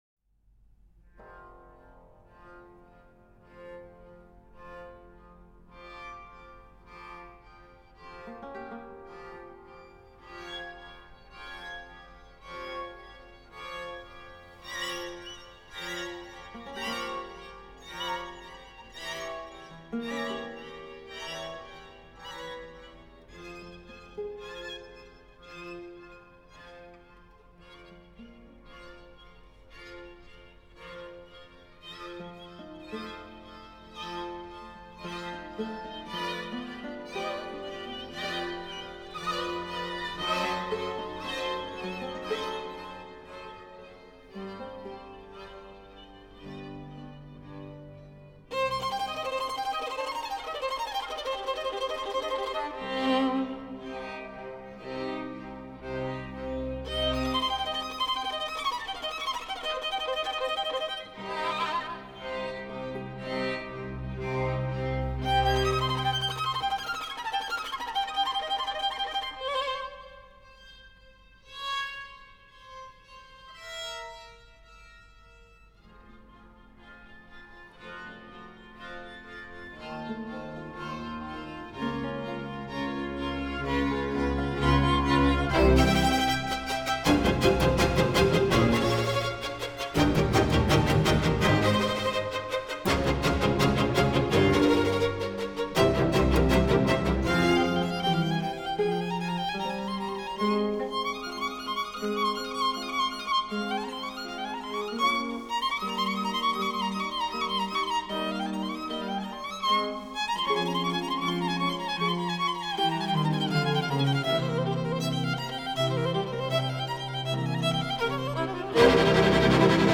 他们的演奏少有平淡，用凶狠来形容一点不过分。时常用弓凶狠、吹奏猛烈，拨弦凌厉。使400年前的音乐充满活力和攻击性。